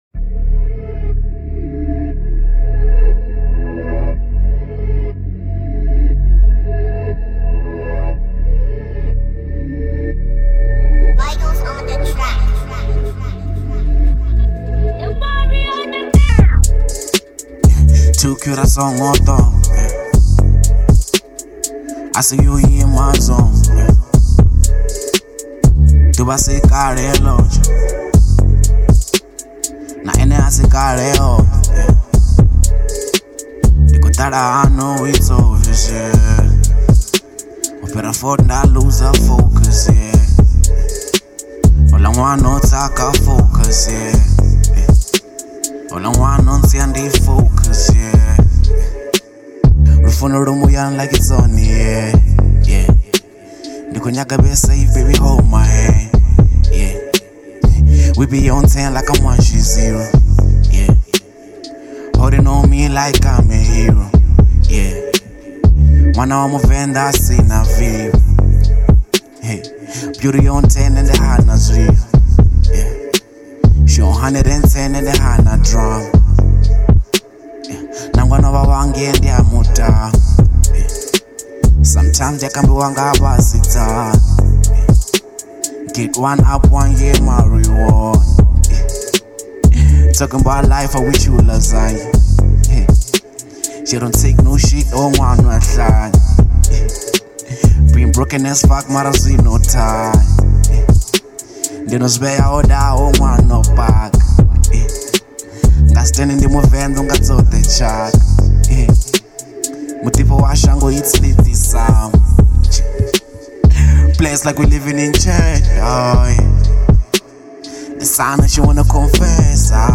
02:46 Genre : Venrap Size